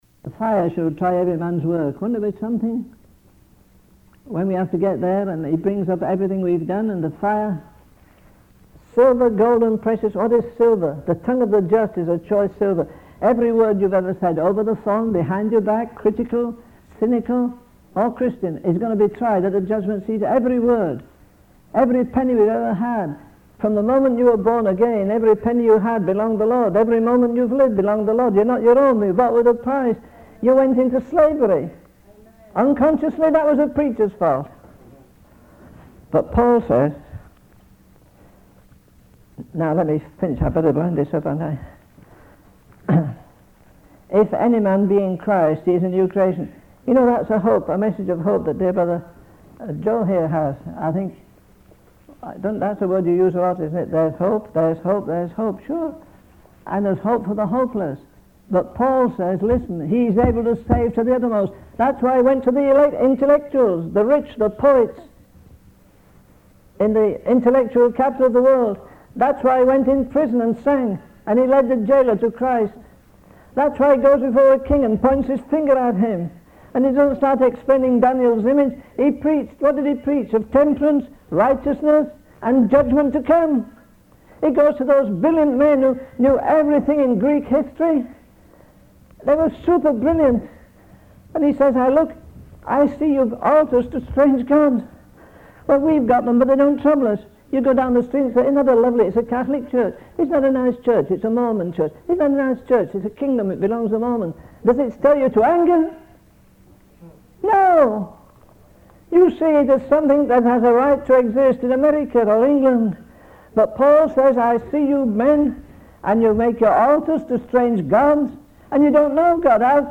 In this sermon, the preacher reflects on the impact of the Apostle Paul's teachings and the great things he accomplished despite facing numerous challenges.